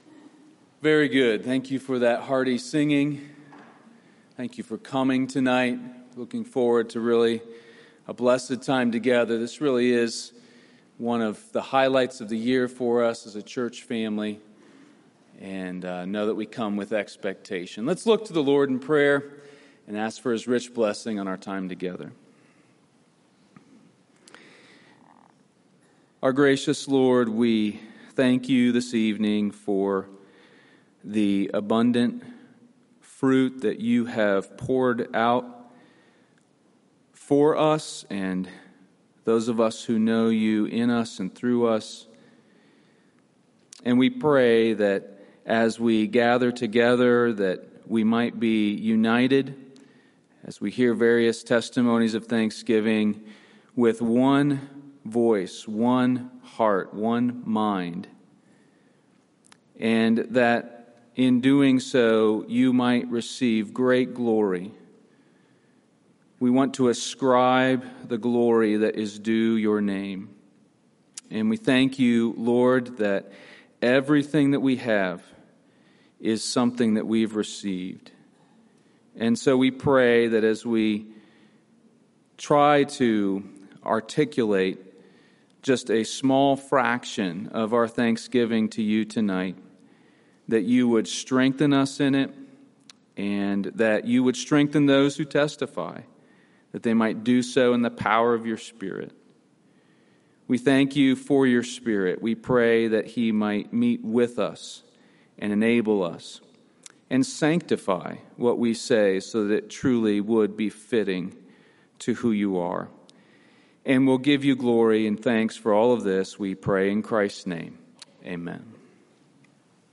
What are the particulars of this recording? Sermons from the Mount Calvary Baptist Church Pulpit in Greenville South Carolina